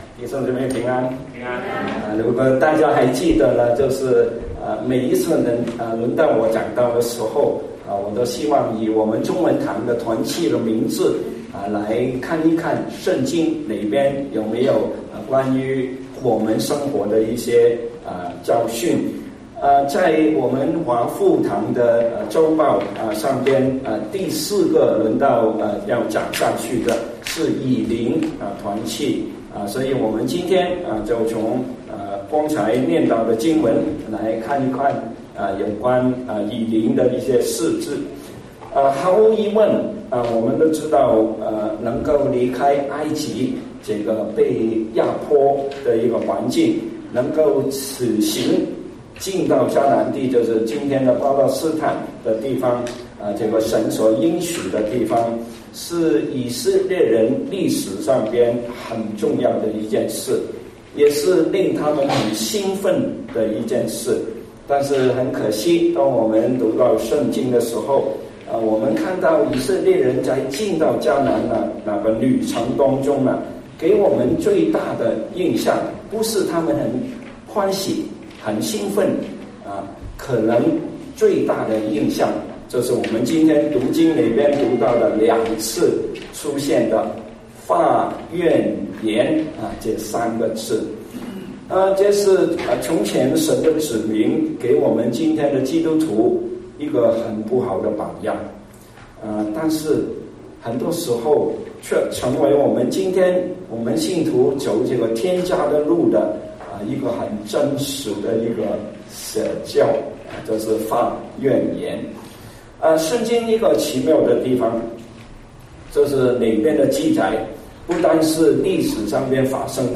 牛頓國語崇拜